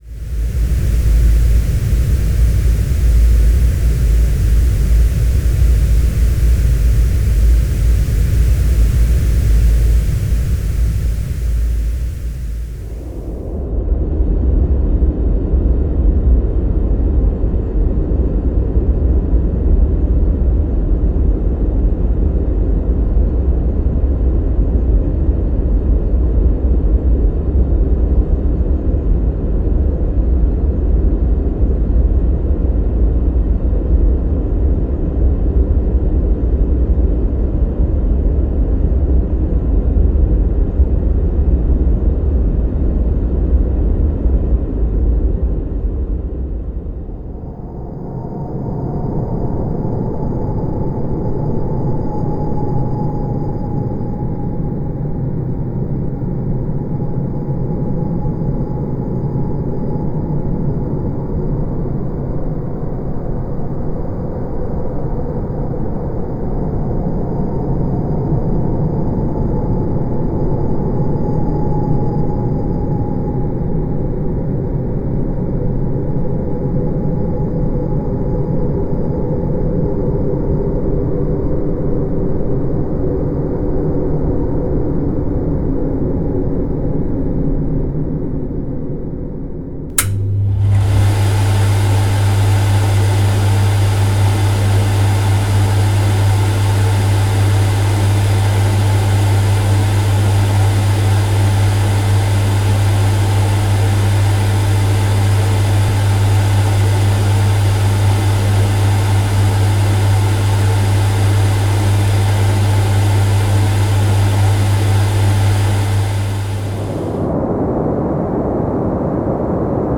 Compilation XXL : Bruit Blanc, Bruit Rose, Bruit Marron, Bruits Naturels Apaisants
Le son constant du bruit blanc (White Noise en anglais), du bruit rose (Pink Noise) et du bruit marron (Brown Noise) peut s'avérer d'une aide précieuse dans notre quotidien.